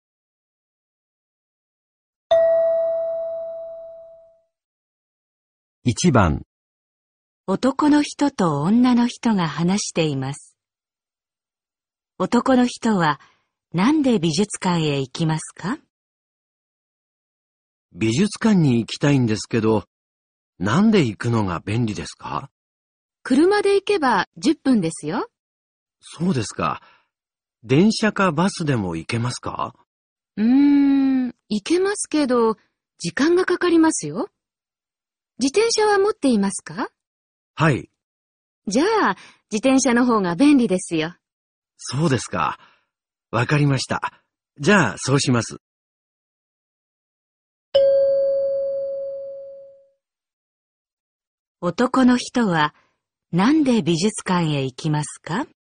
男人和女人在说话。